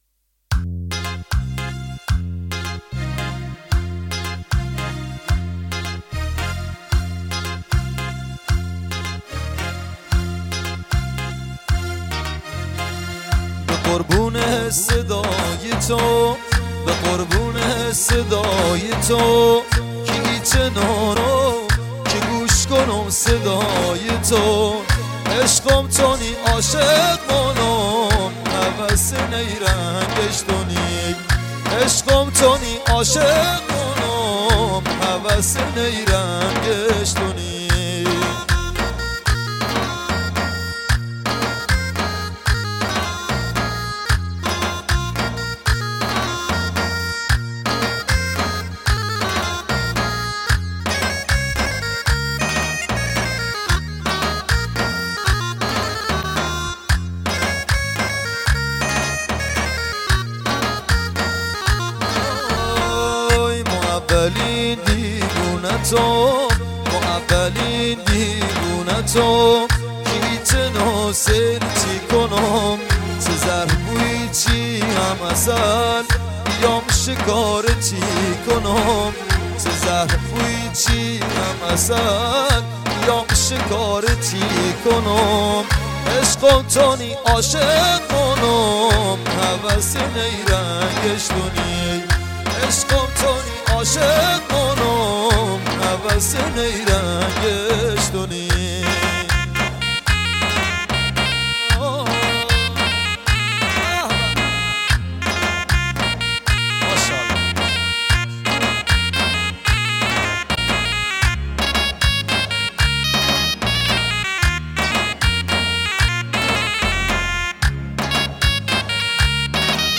موزیک احساسی لری
Lori music
مجلسی غمگین